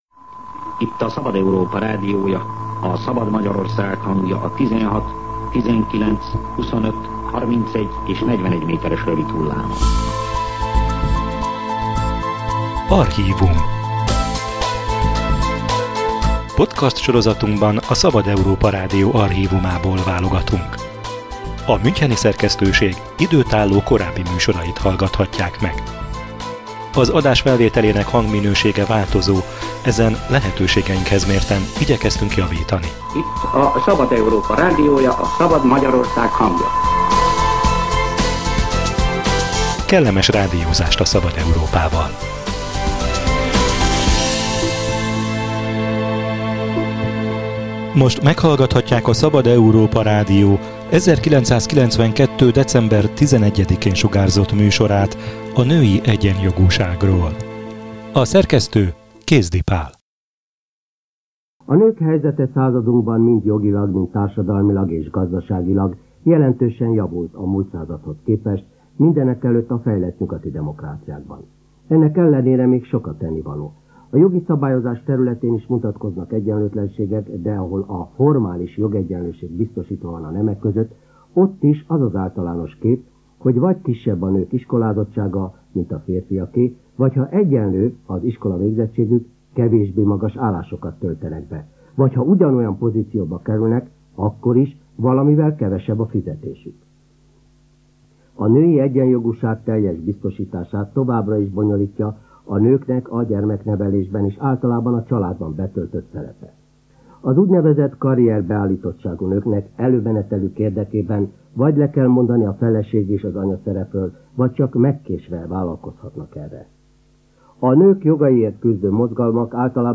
Amikor új fogalomnak számított a munkahelyi zaklatás – archív műsor a női egyenjogúságról
A karrier és a család összehangolása harminc évvel ezelőtt is ismert téma volt, de a nők munkahelyi szexuális zaklatásáról kevés érintett mert beszélni. A Szabad Európa Rádió 1992-es műsora nyugati országok gyakorlatát mutatja be a visszaélések megfékezésére és az egyenlő feltételek biztosítására.